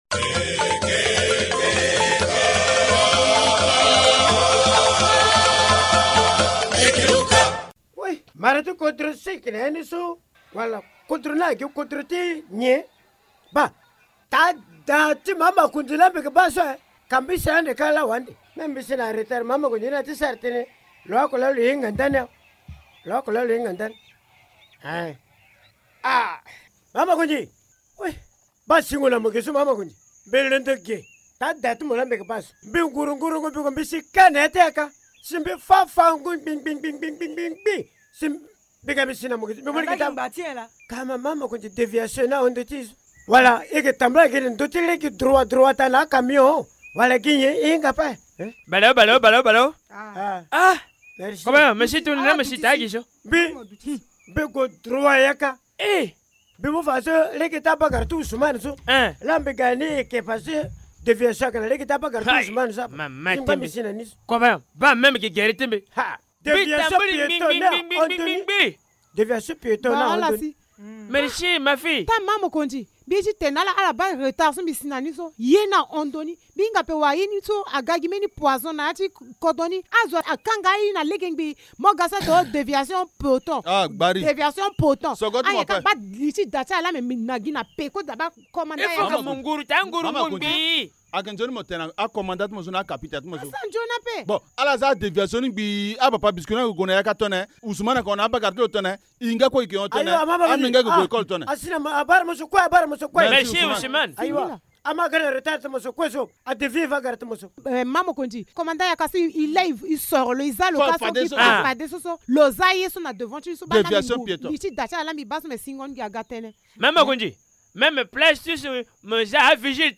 Linga théâtre : le village Linga submergé par des panneaux de déviation